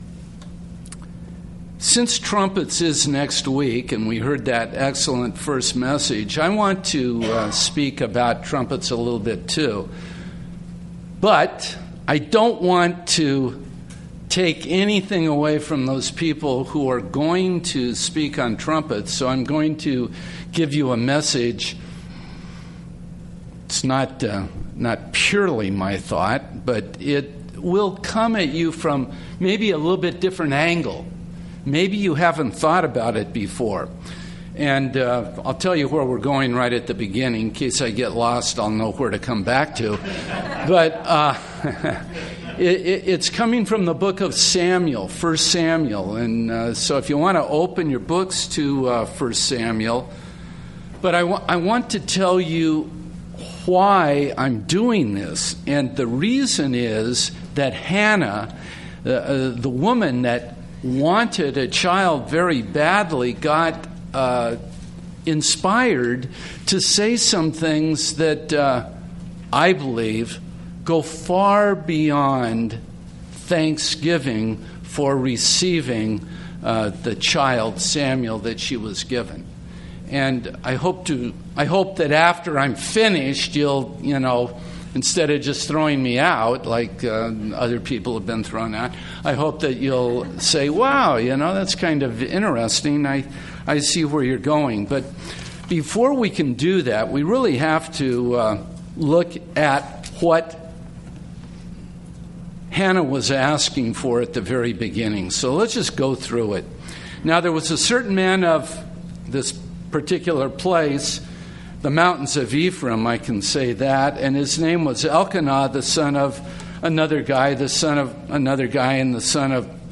Given in Redlands, CA
UCG Sermon